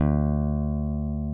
P-B PICK D3.wav